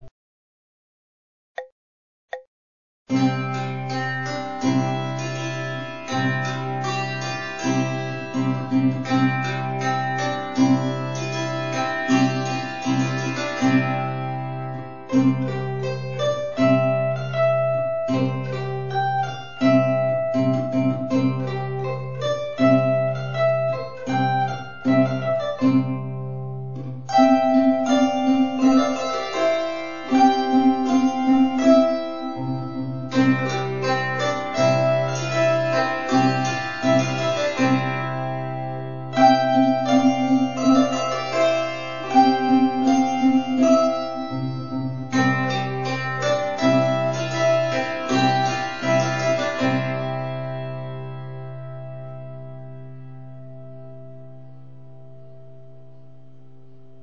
Neoborduner Tanz in C (ohne Bass)